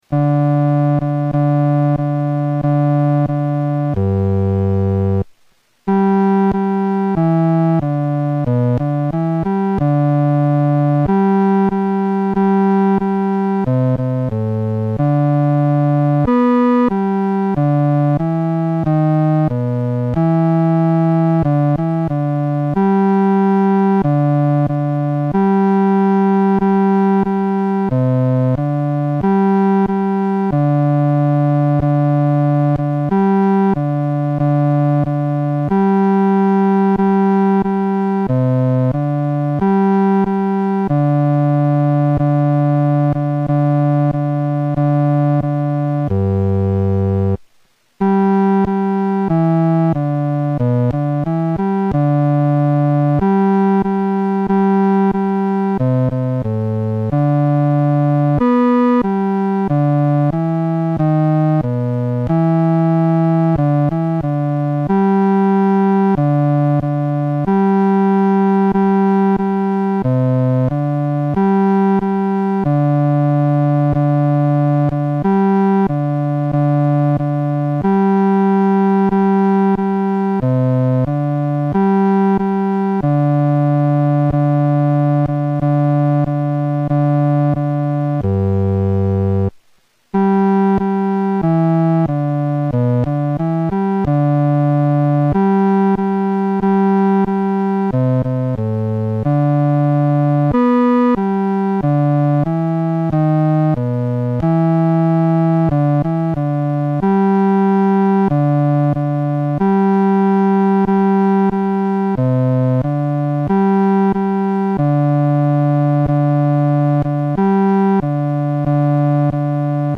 伴奏
四声
这是他第一次为赞美诗谱曲，写的曲调有民间风味，且简单易唱，富有活力。